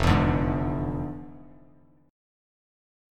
FmM13 chord